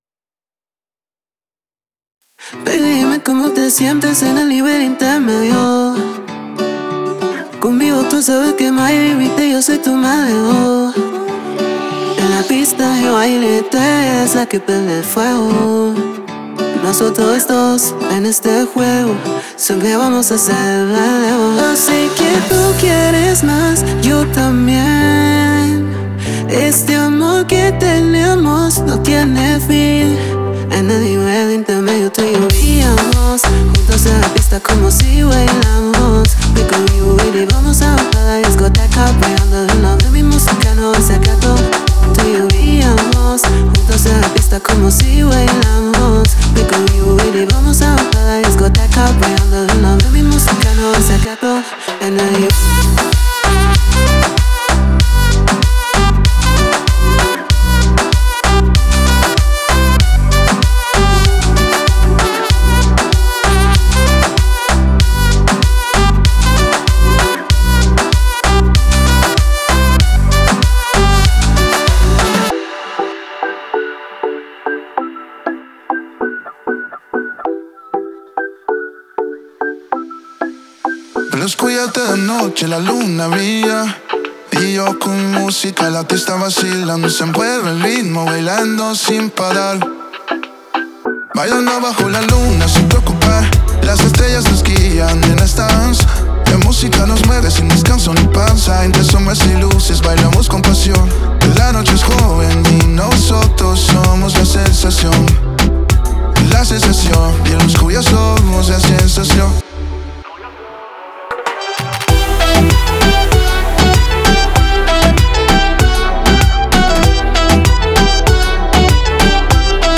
Genre:Latin
サウンドクオリティは極めて純度が高く、それぞれの要素が完璧にミックスに馴染むよう緻密に設計されています。
デモサウンドはコチラ↓